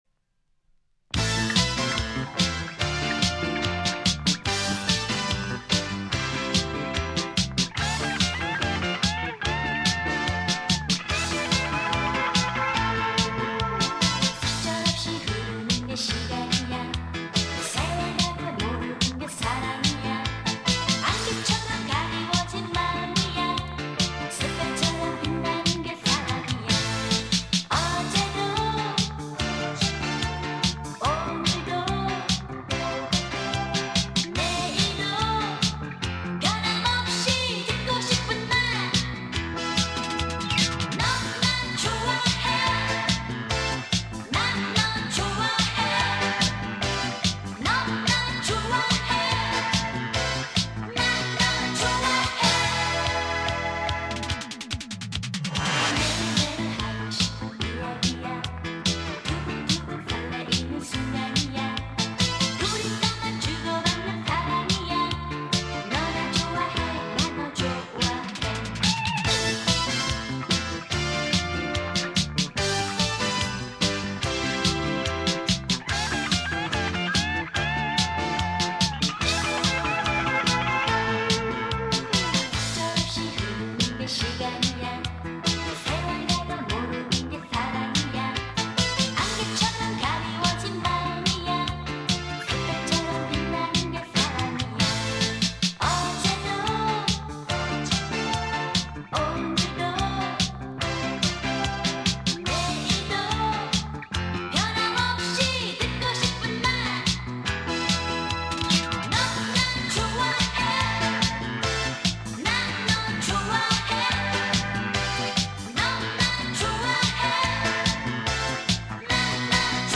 대한민국의 여성 싱어송라이터, 작사가, 작곡가, 음반 프로듀서, 배우이다.